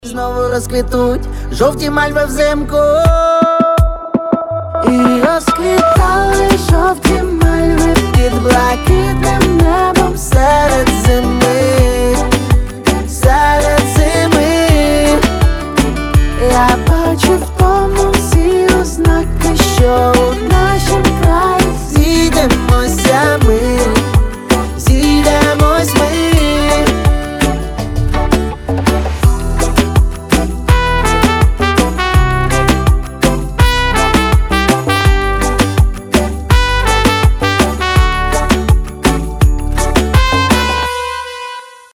мужской вокал
добрые